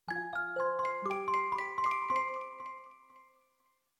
Old Music Box 5
bonus-sound film-production game-development intro magic music-box mystic mystical sound effect free sound royalty free Music